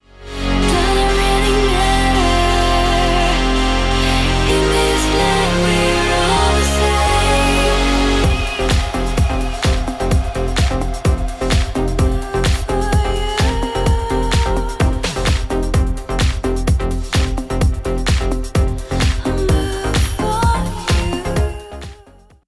DIY Record Cutting Lathe is Really Groovy
Of course for a stereo record, the wiggling needs to be two-axis, and for stereo HiFi you need that wiggling to be very precise over a very large range of frequencies (7 Hz to 50 kHz, to match the pros).
That necessitated a better amplifier, which helped improve frequency response.